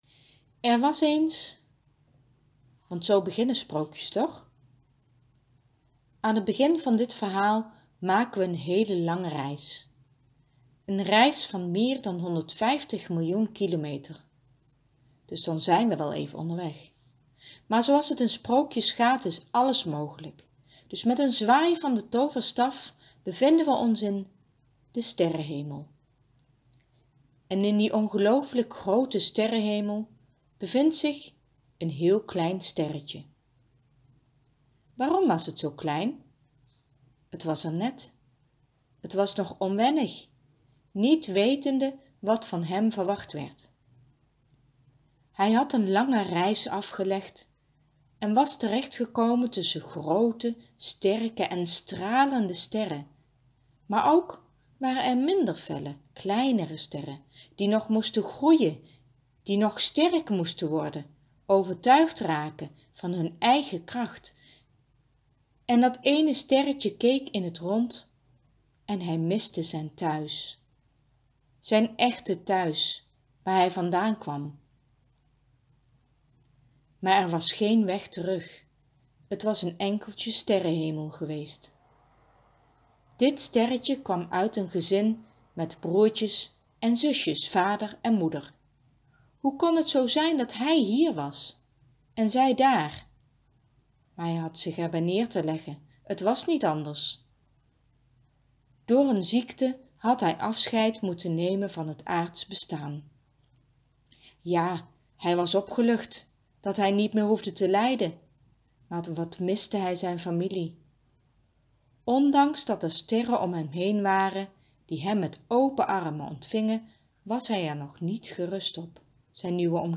Als je het leuk vindt, lees ik hem je graag voor.